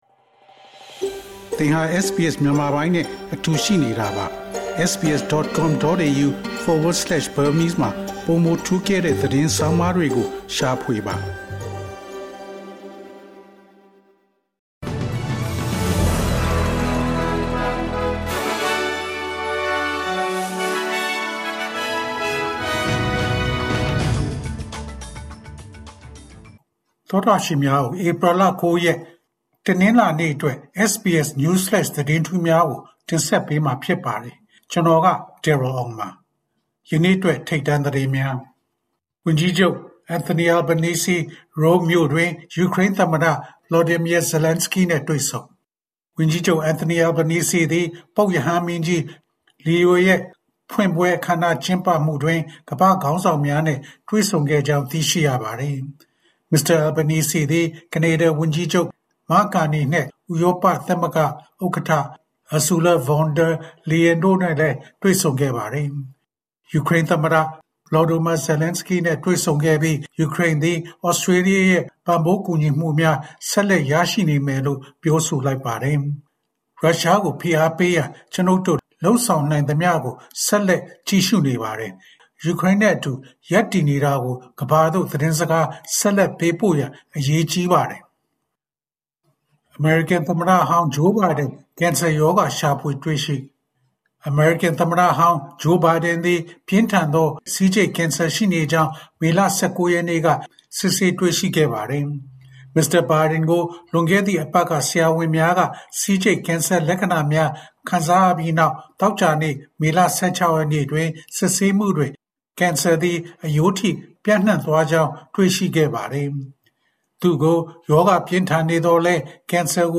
ALC: ၂၀၂၅ ခုနှစ် မေလ ၁၉ ရက်, SBS Burmese News Flash သတင်းများ။